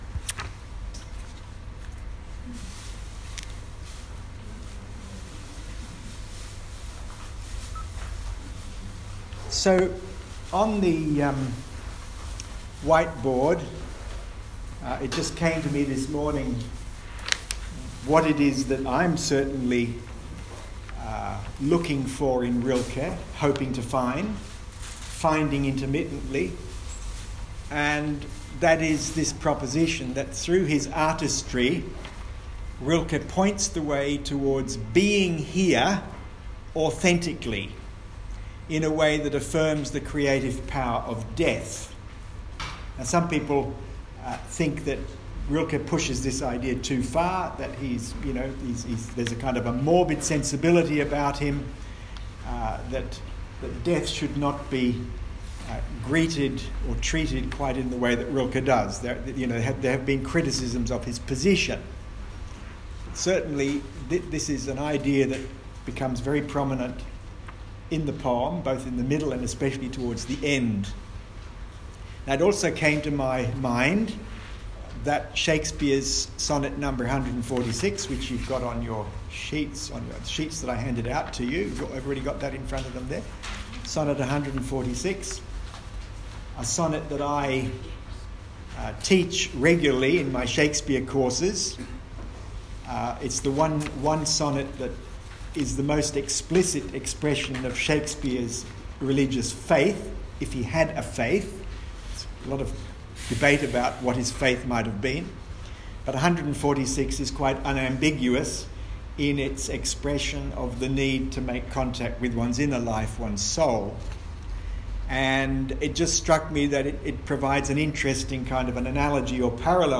Here is the audio of how our discussion unfolded and this is followed by the visual images used to support our discussion: